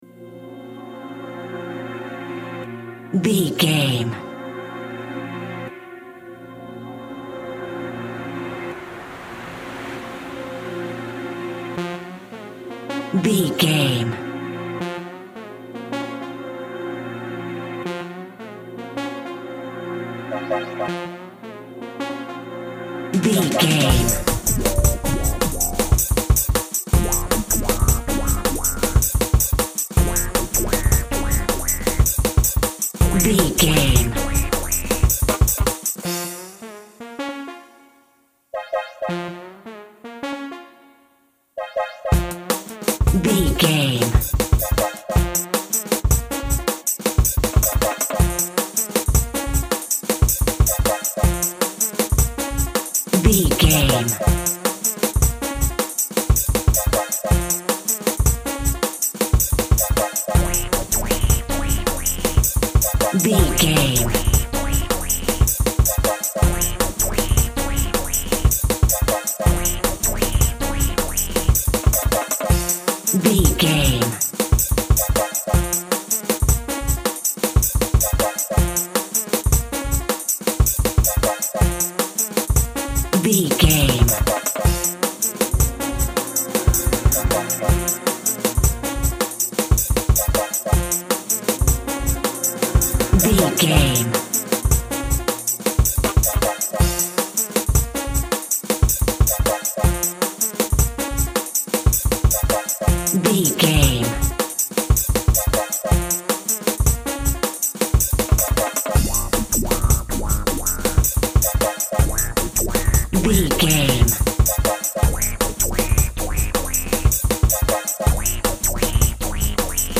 Fast
futuristic
hypnotic
industrial
mechanical
dreamy
frantic
synthesiser
drums
chill out
nu jazz
downtempo
synth lead
synth bass